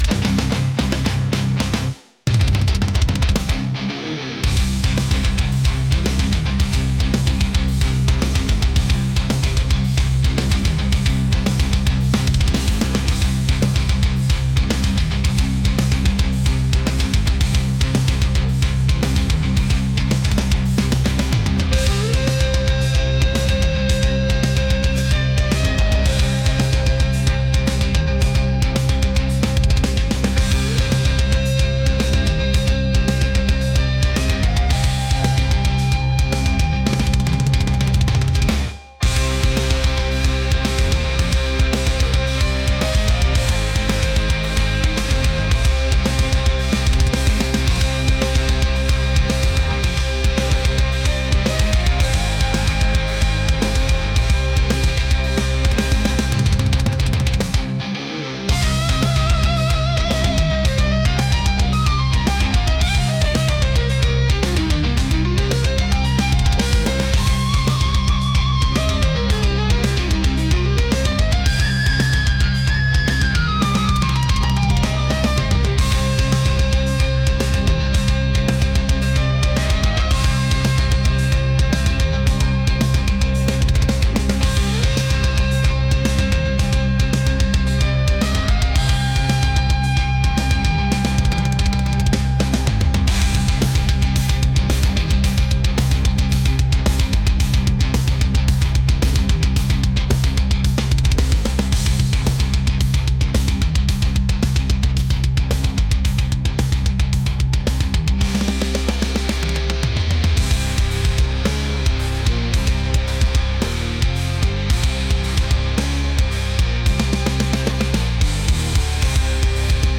aggressive | metal | heavy